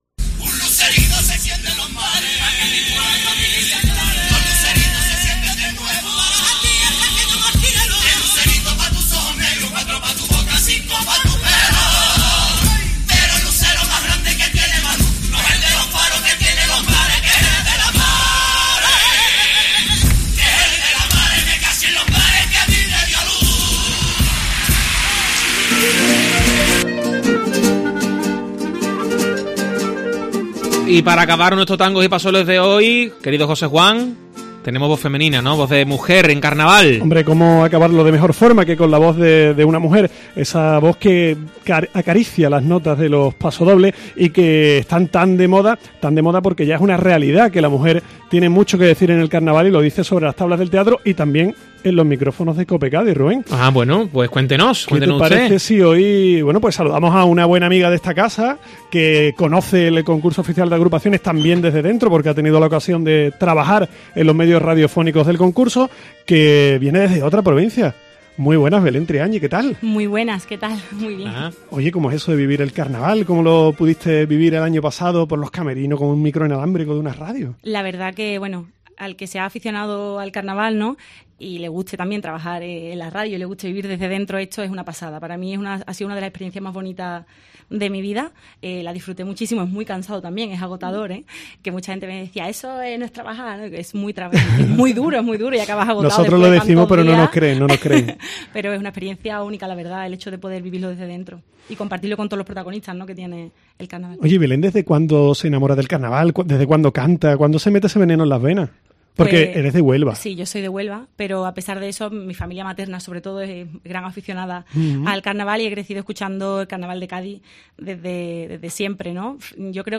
pasodoble
guitarra
Carnaval